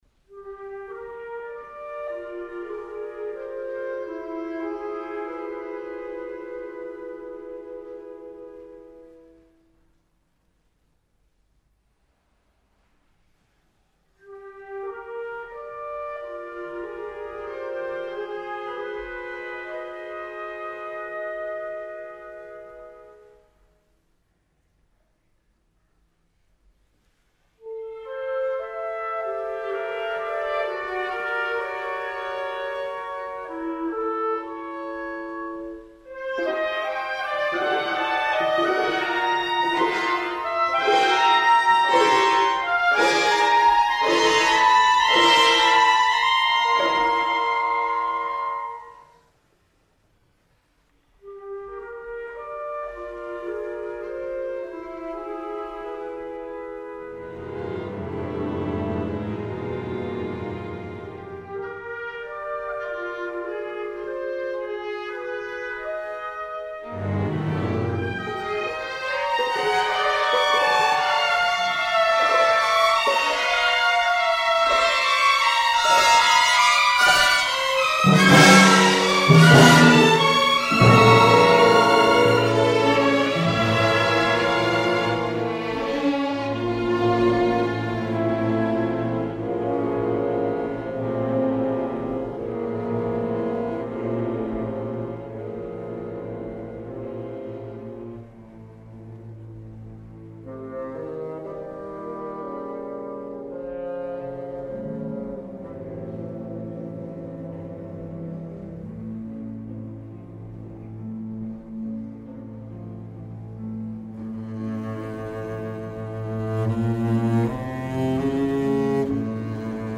• Category: Contrabass